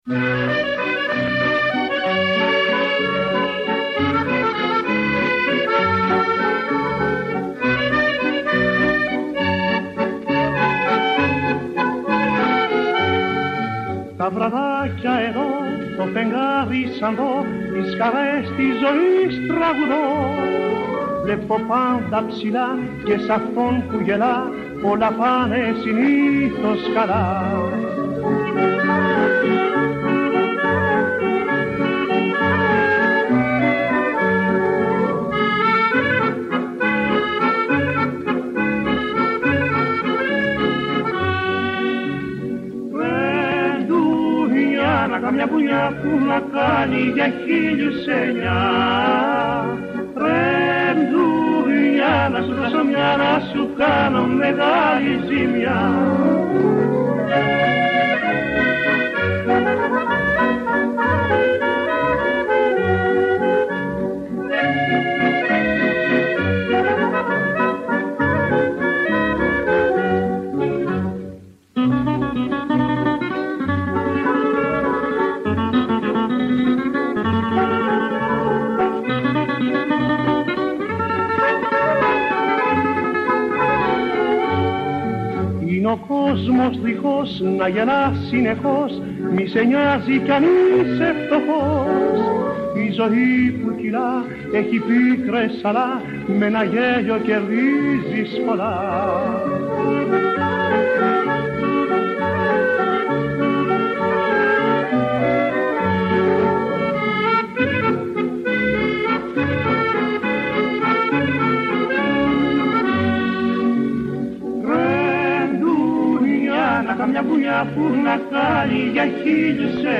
σε μια εφ’ όλης της ύλης συνέντευξη